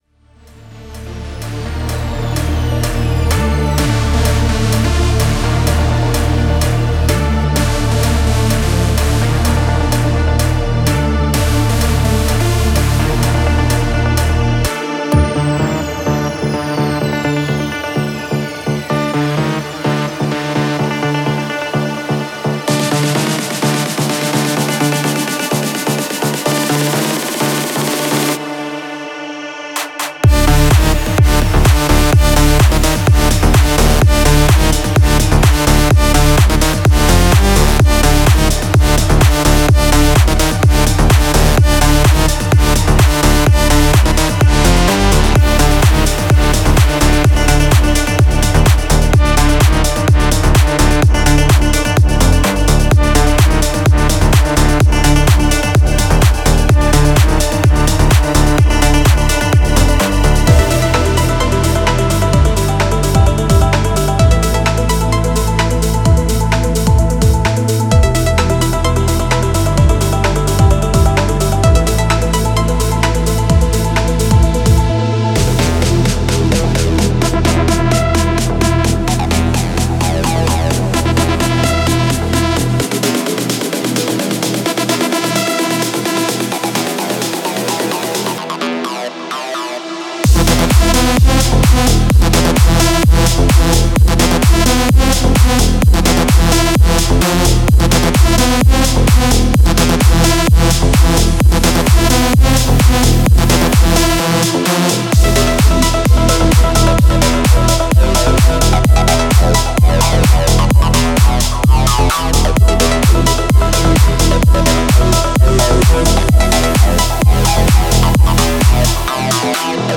Type: Serum Midi Samples
Big Room Future Rave Progressive House